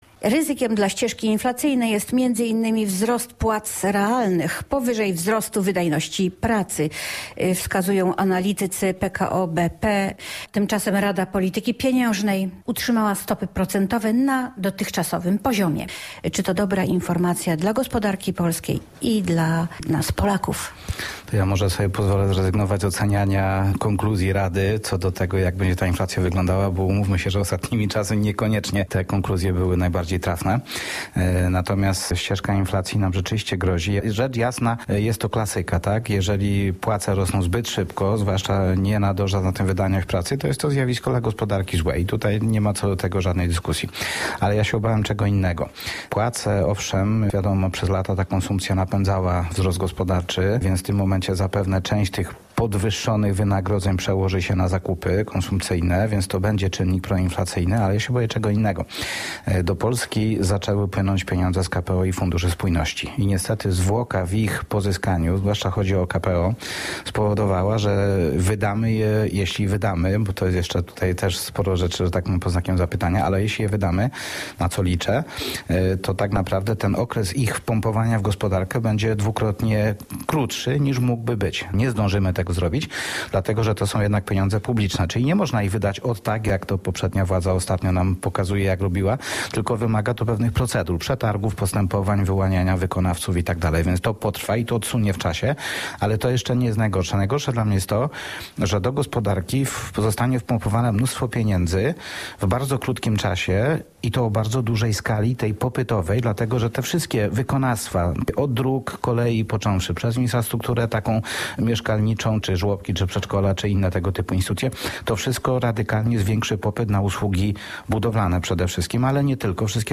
gościem audycji Polskiego Radia Rzeszów, w której poruszono tematy takie jak: inflacja w Polsce, inwestowanie środków z KPO, handel z Ukrainą i Rosją. Dodatkowo rozmawiano o umiejętnościach kobiet w zarządzaniu budżetem domowym.